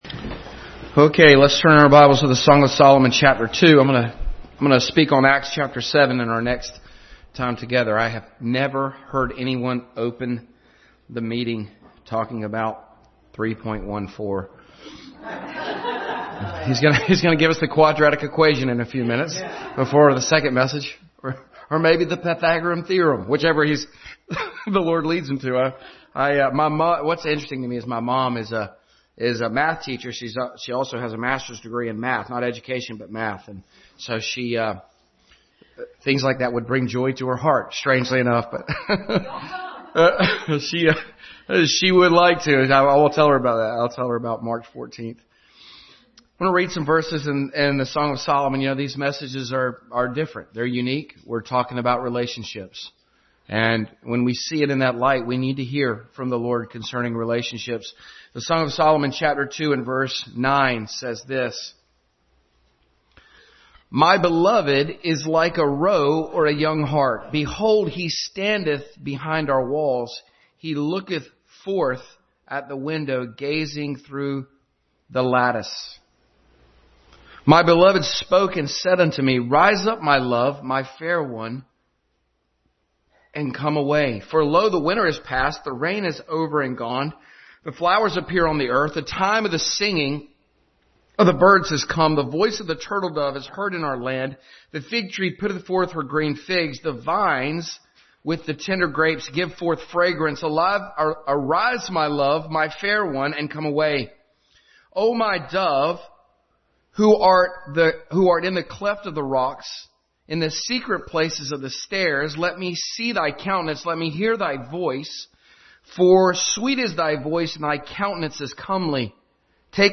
Passage: Song of Solomon 2:9-15, Matthew 5:9, Philippians 4:8 Service Type: Sunday School